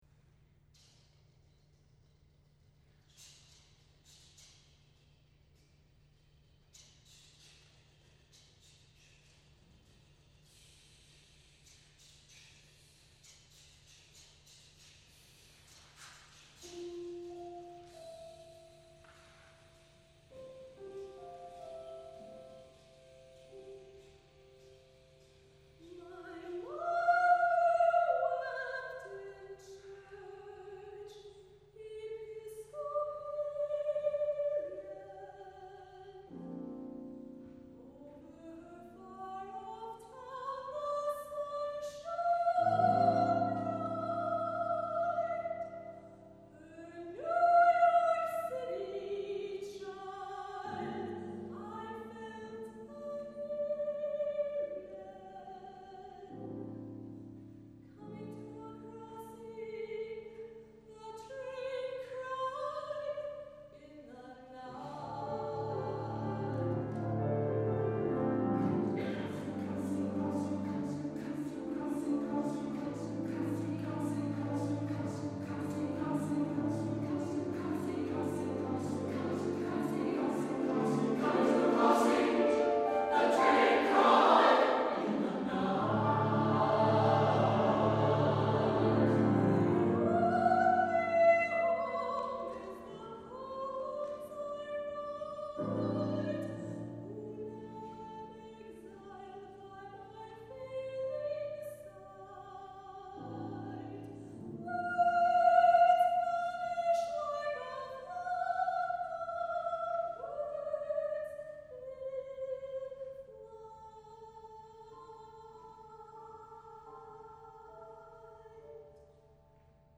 for SATB Chorus and Piano (1998)